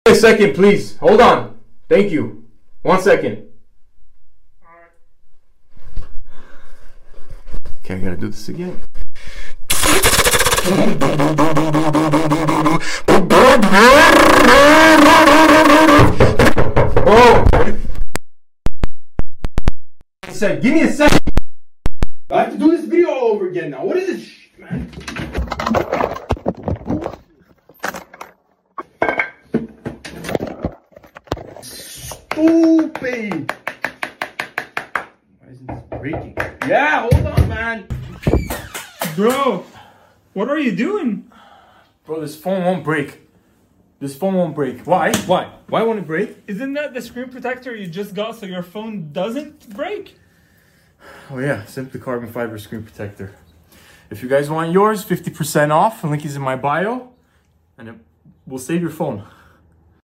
RX7 BRAP BRAP (I Sound Effects Free Download